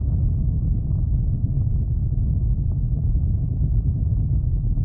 Index of /sound/ambient/atmosphere/
cave_outdoor1.mp3